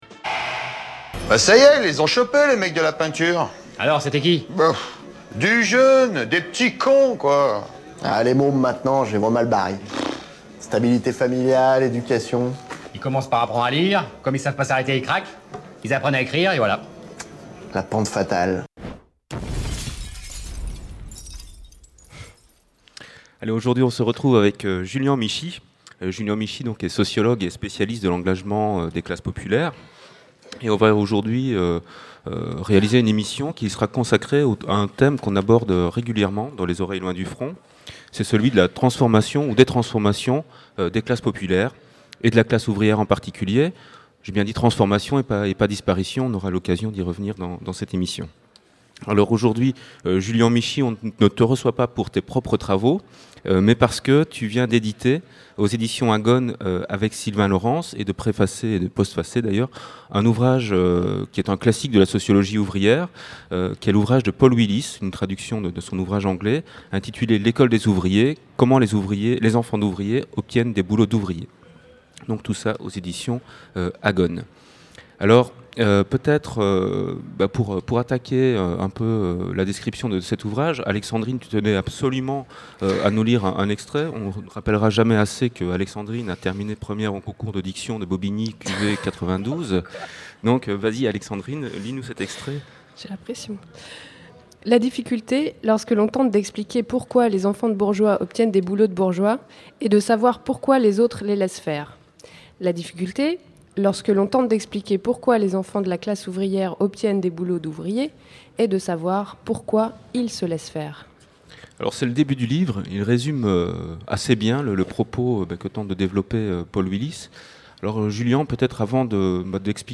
L'émission en public concoctée par Les Oreilles Loin du Front à l'occasion du Festival "FPP part en Live", qui s'est déroulé à la Ligne 13 à Saint-Denis les 30 septembre et 1er octobre 2011.